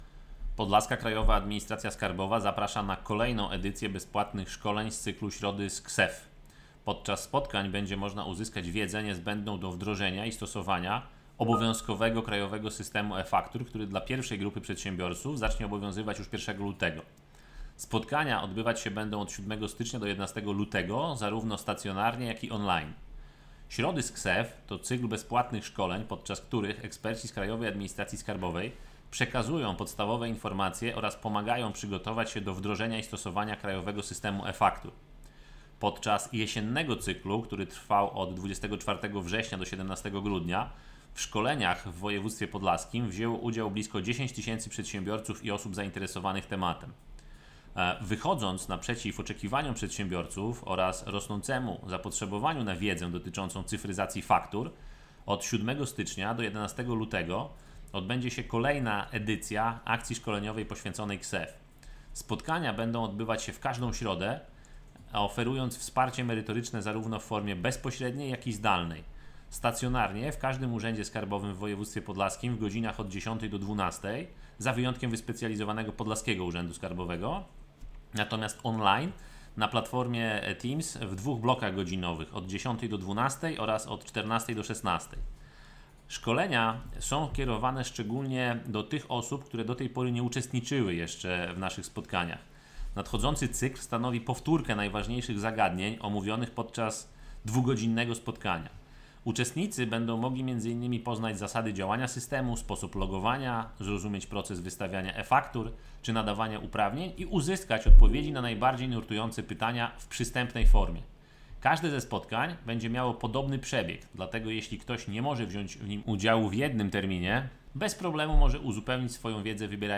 Wracają szkolenia z cyklu Środy z KSeF (wypowiedź